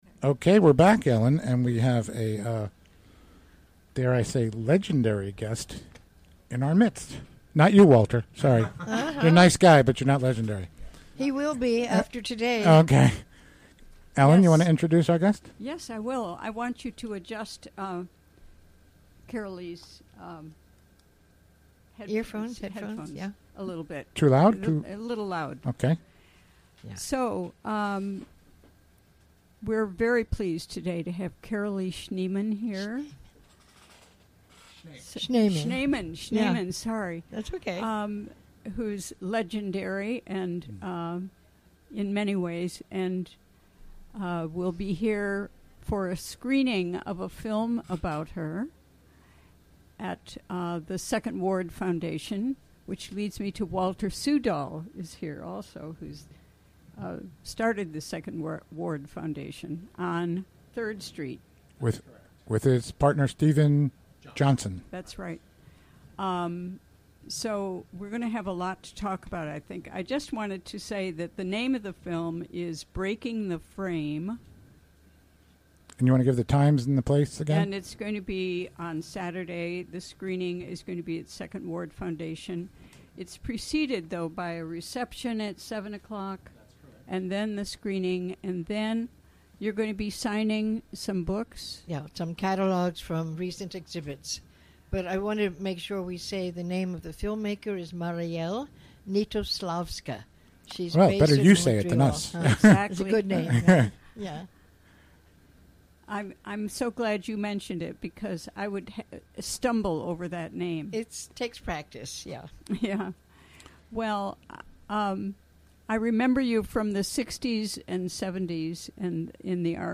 Recorded during the WGXC Afternoon Show on Thursday July 14, 2016.